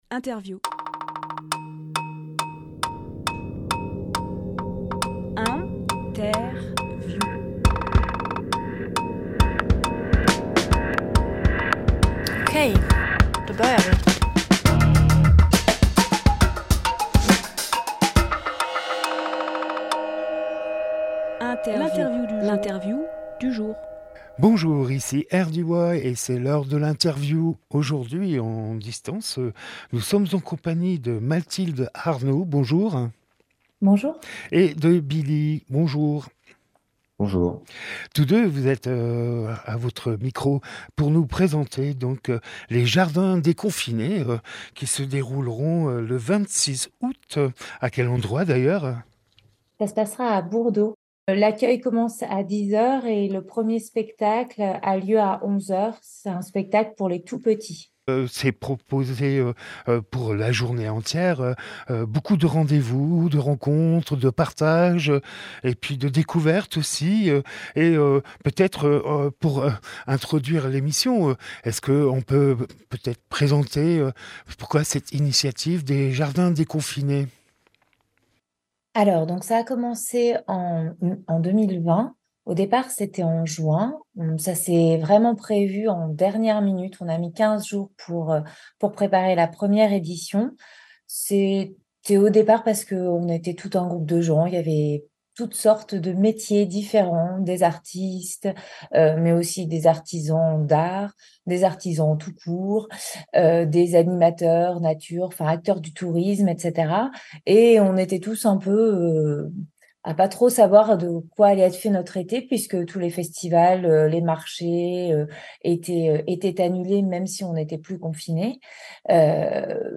Emission - Interview Festival Les Jardins déconfinés de Bourdeaux Publié le 19 août 2023 Partager sur…
10.08.23 Lieu : Studio RDWA Durée